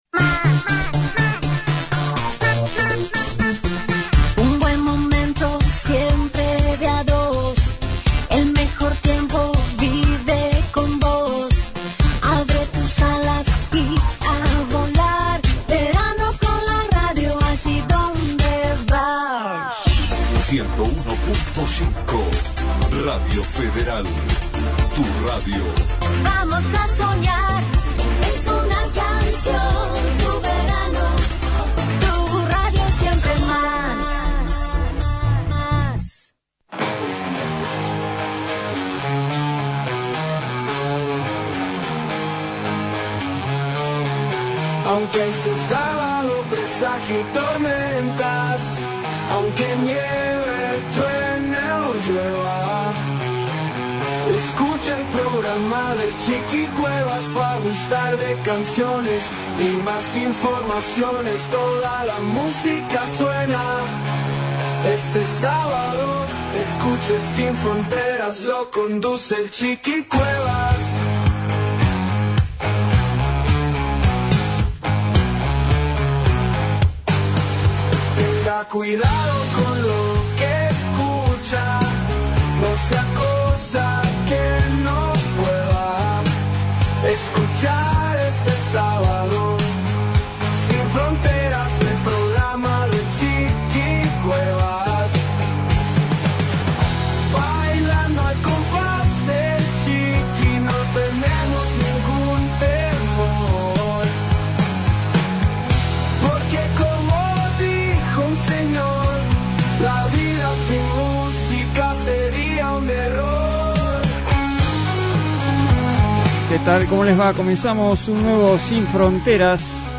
MUSICA - Rock Clásicos e Internacionales